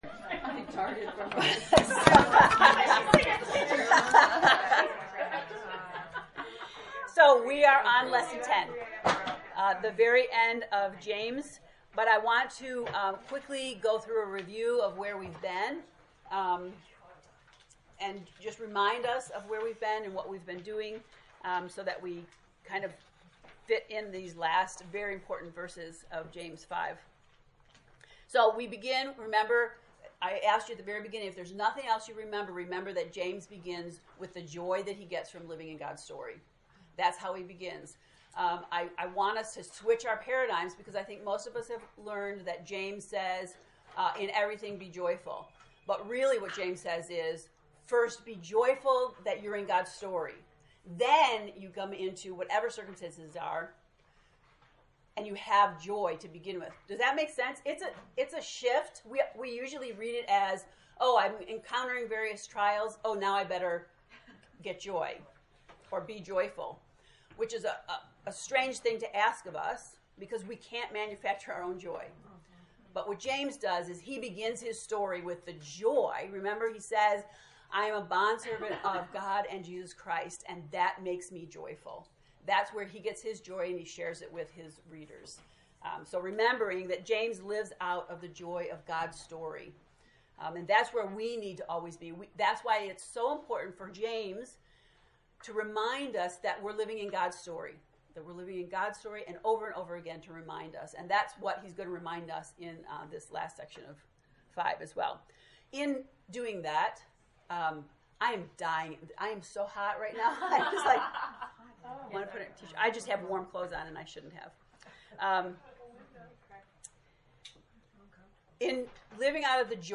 To listen to the lesson 10 lecture, “Stitching Prayer,” click below: